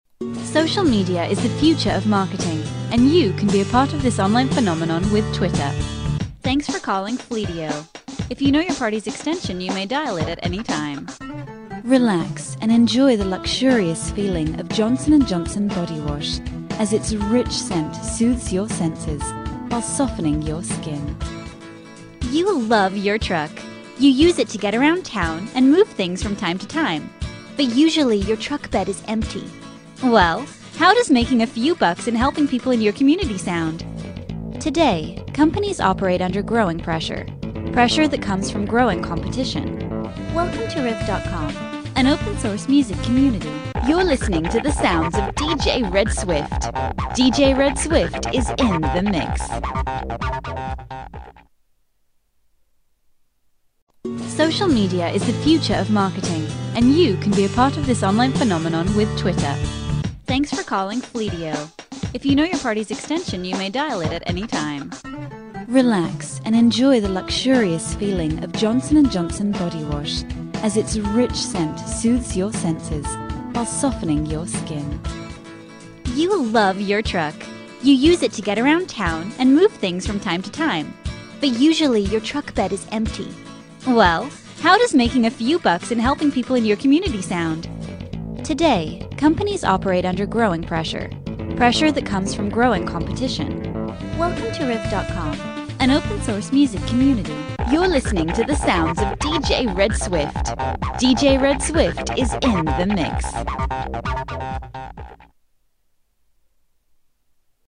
英式英语青年沉稳 、娓娓道来 、科技感 、积极向上 、时尚活力 、神秘性感 、亲切甜美 、素人 、女专题片 、宣传片 、广告 、飞碟说/MG 、课件PPT 、工程介绍 、绘本故事 、动漫动画游戏影视 、旅游导览 、微电影旁白/内心独白 、80元/百单词女英1 英式英语 广告 MG动画 解说宣传 沉稳|娓娓道来|科技感|积极向上|时尚活力|神秘性感|亲切甜美|素人
女英1 英式英语 彩铃 沉稳|娓娓道来|科技感|积极向上|时尚活力|神秘性感|亲切甜美|素人